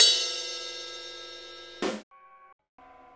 CYM RIDE408L.wav